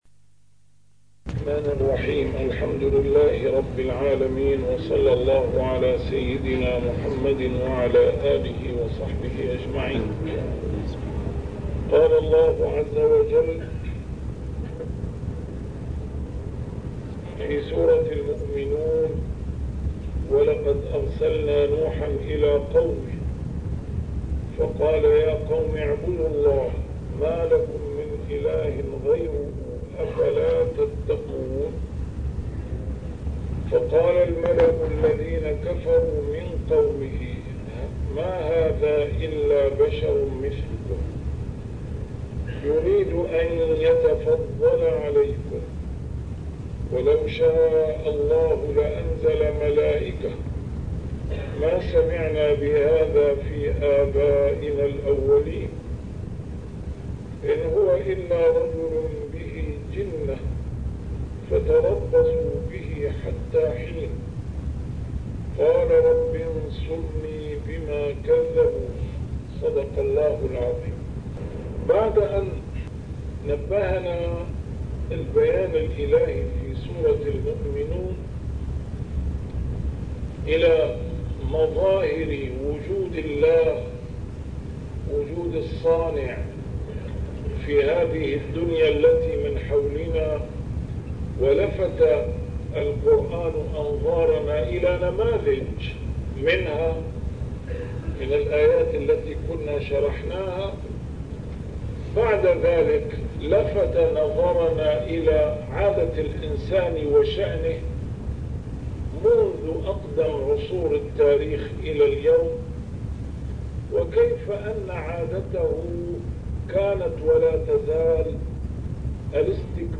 A MARTYR SCHOLAR: IMAM MUHAMMAD SAEED RAMADAN AL-BOUTI - الدروس العلمية - تفسير القرآن الكريم - تسجيل قديم - الدرس 151: المؤمنون 023-025